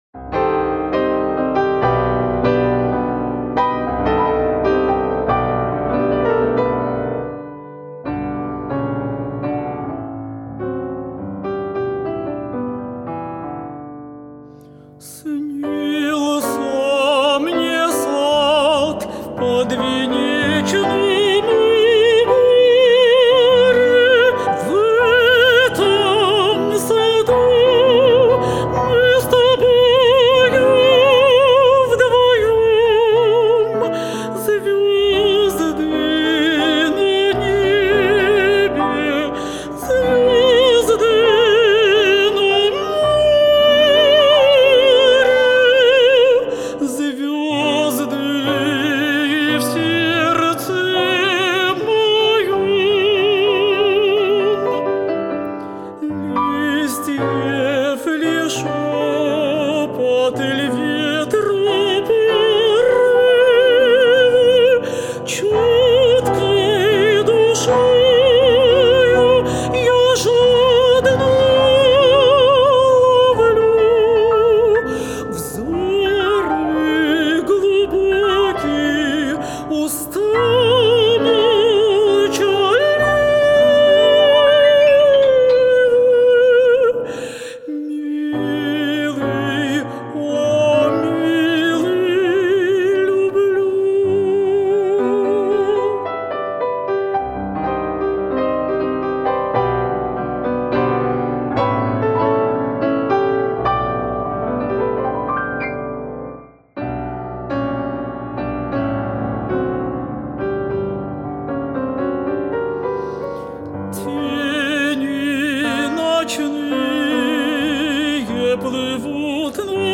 старинный русский романс